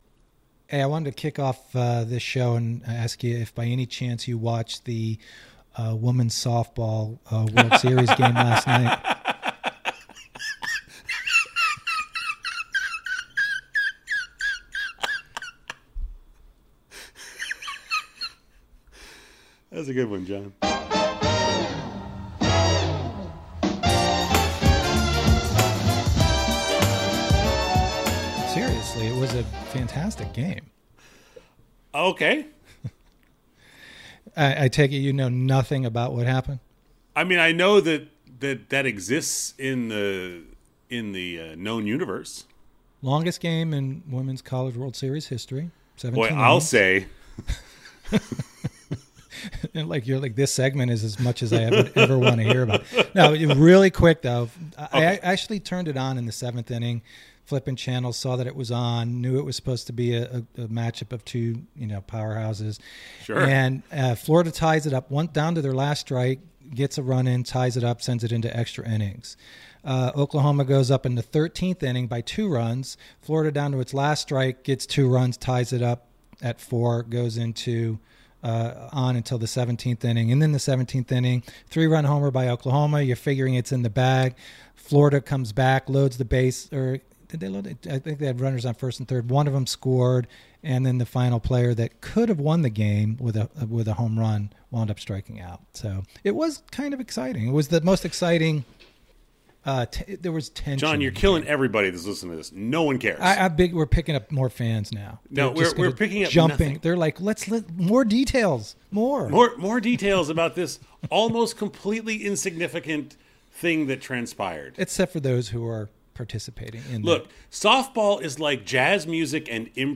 hysterical laughter